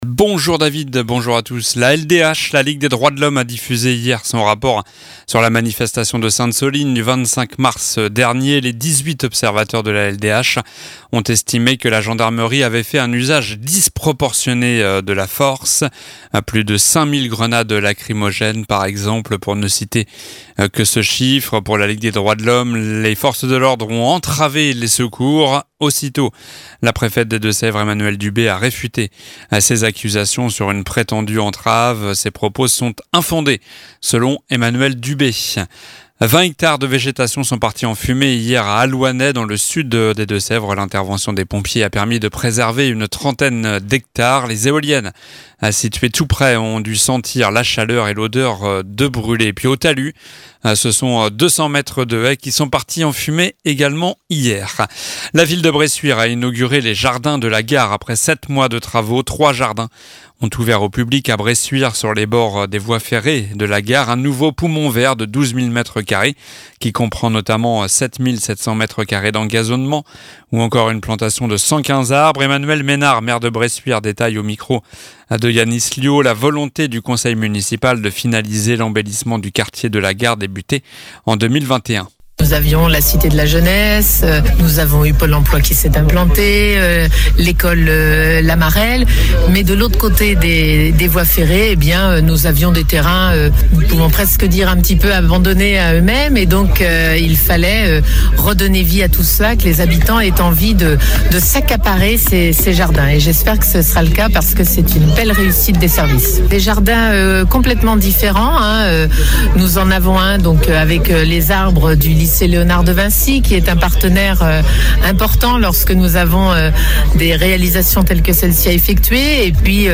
Journal du MARDI 11 juillet (matin)